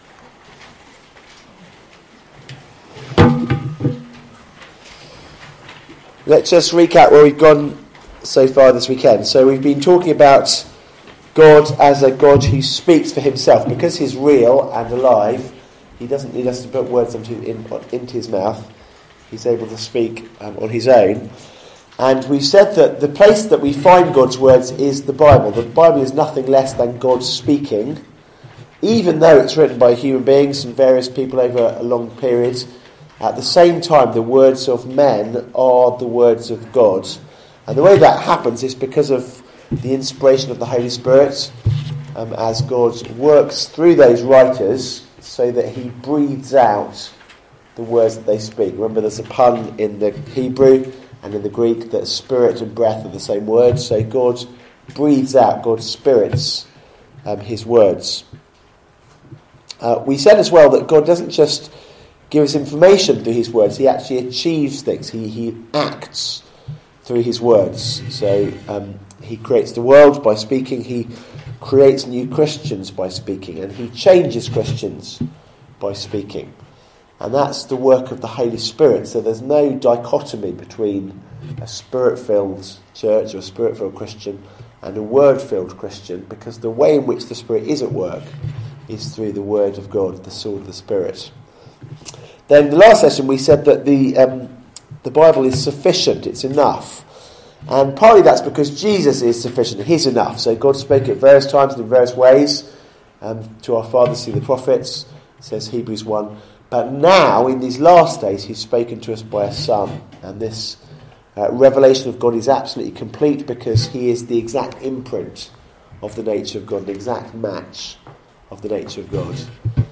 Series: Weekend Away Nov 2016 | Word of God